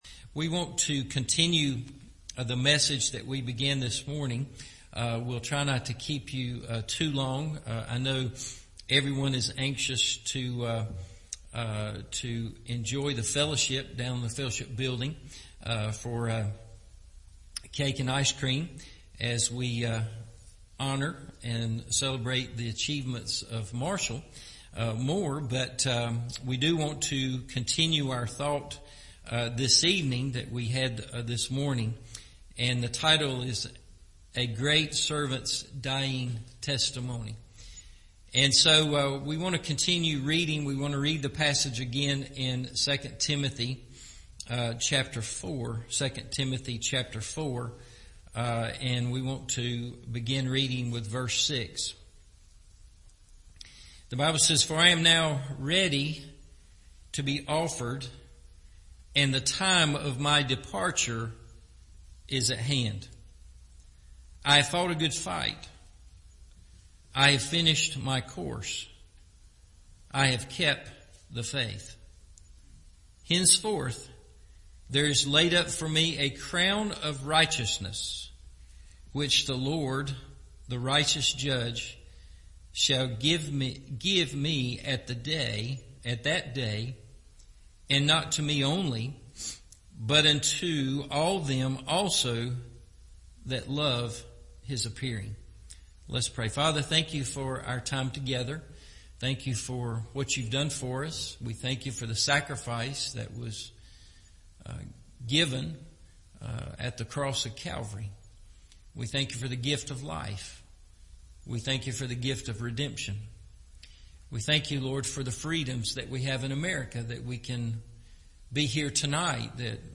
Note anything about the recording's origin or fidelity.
A Great Servant’s Dying Testimony Part Two – Evening Service